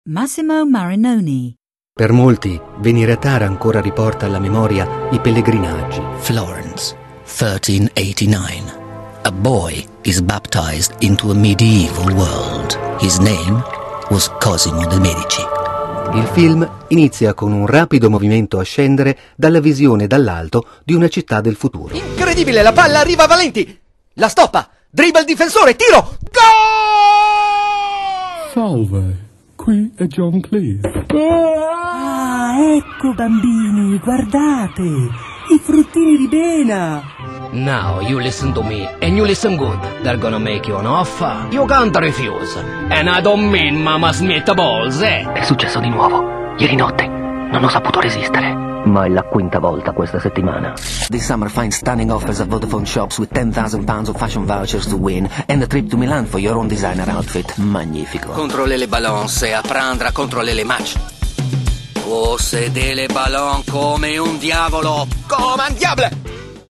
Age range: 40s - 60s
Showreel 0:00 / 0:00